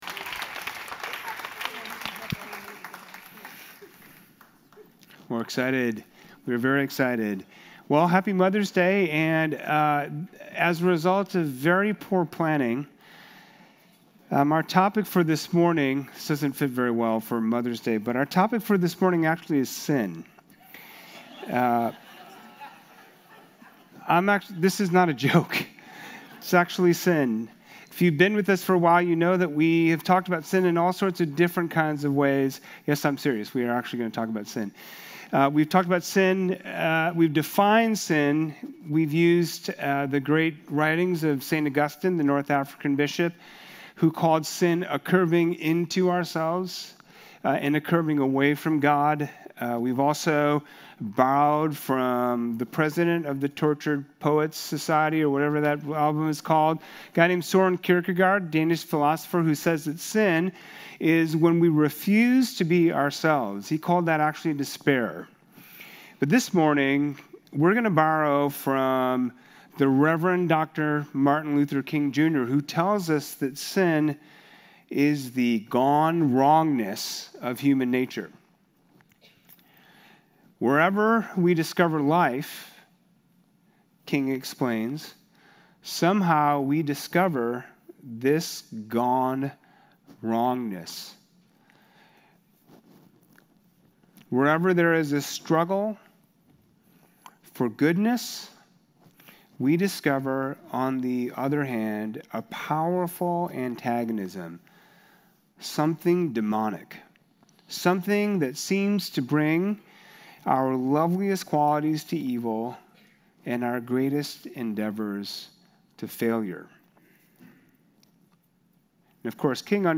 Evanston Vineyard